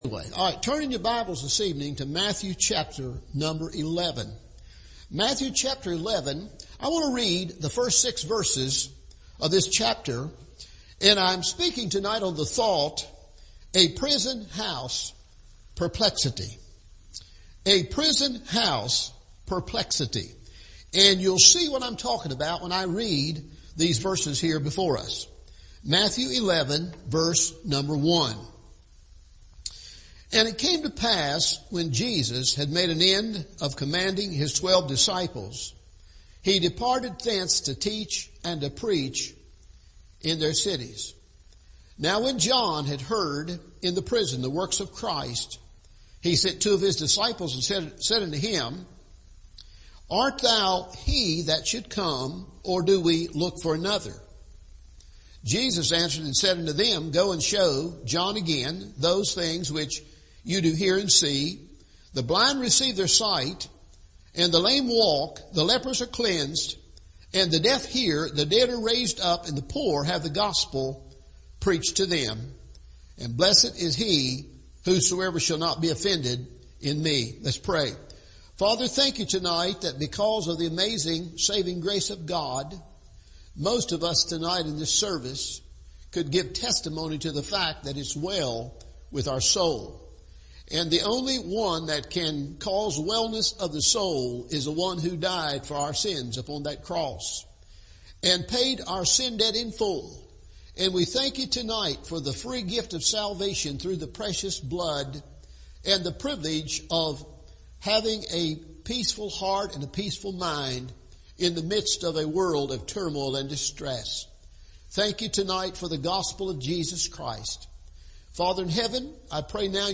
A Prison House Perplexity – Evening Service